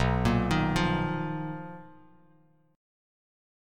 BM#11 chord